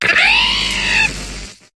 Cri de Luxio dans Pokémon HOME.